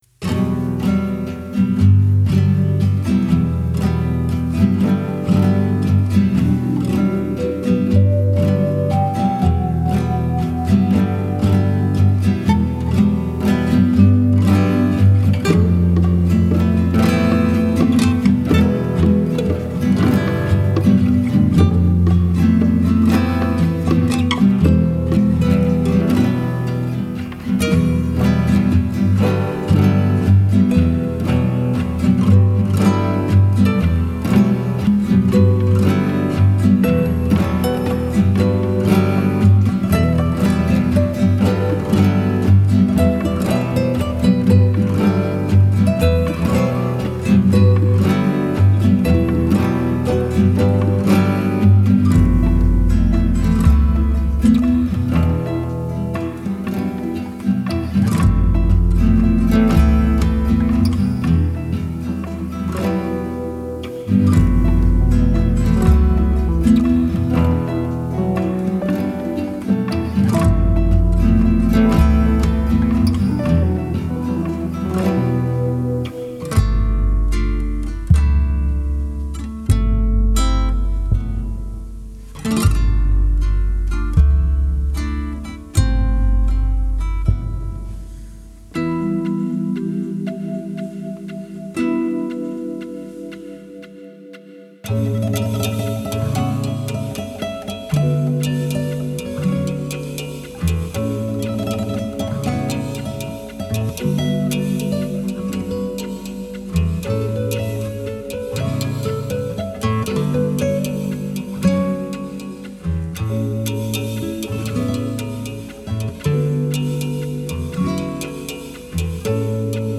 No loops, no AI !